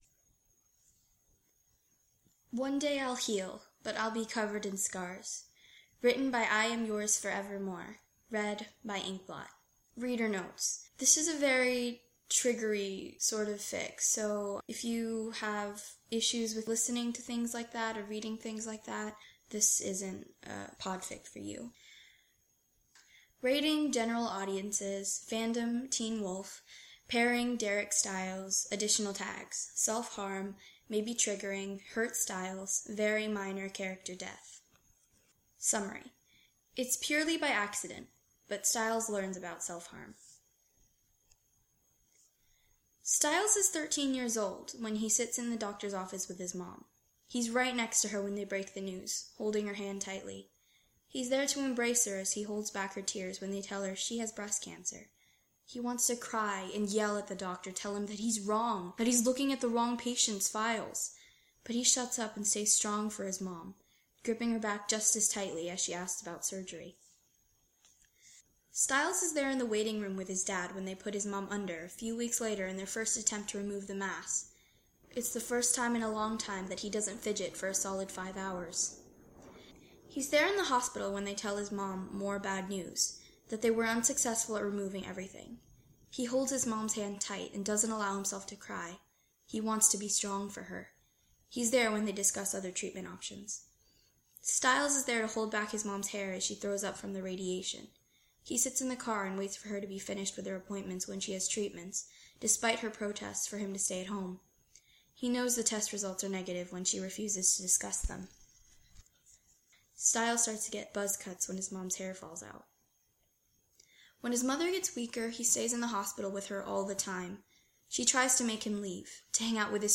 Okay, I'm really sorry, my throat was super raspy, so it's kinda quiet, but eh.